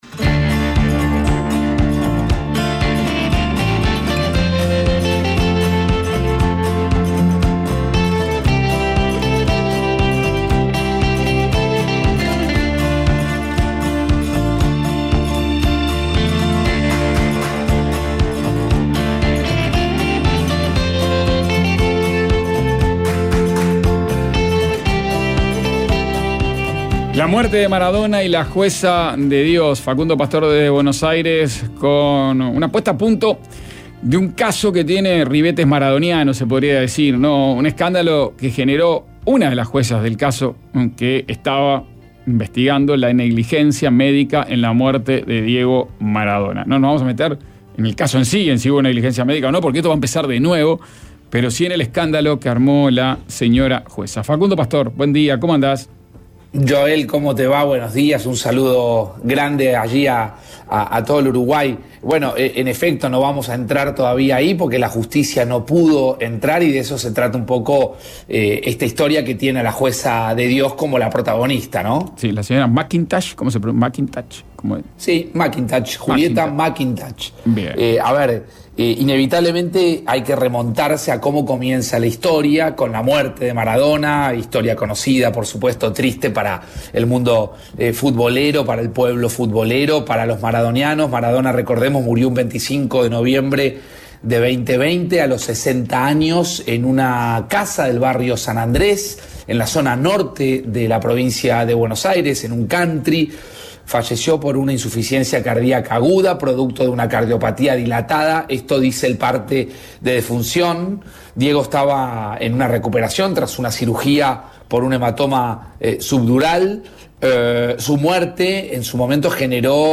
Facundo Pastor entrevistó a Cositorto y contó la historia de esta estafa piramidal